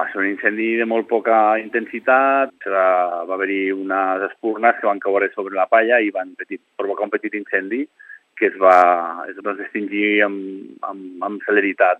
n’ha parlat als micròfons d’aquesta casa.